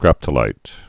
(grăptə-līt)